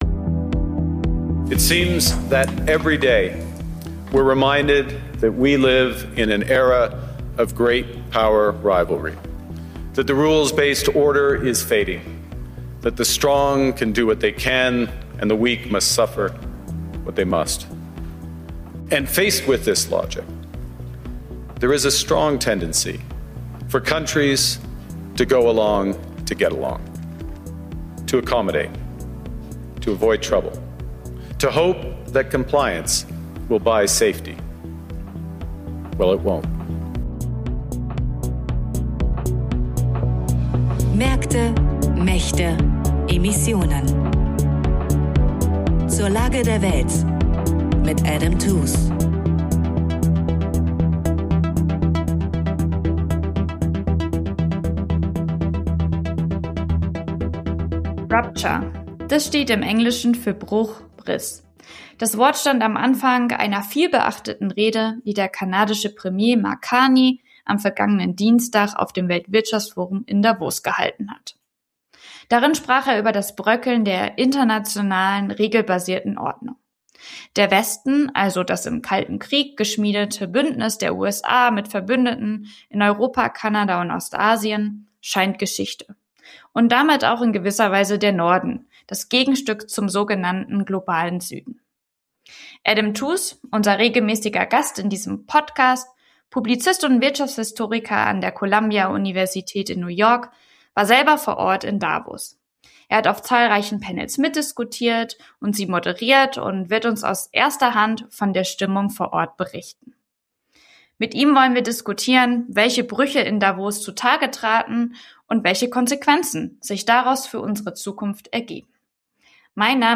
Das Weltwirtschaftsforum in Davos markiert einen Bruch zwischen den westlichen Ländern. Der Wirtschaftshistoriker Adam Tooze berichtet von seinen Eindrücken vor Ort.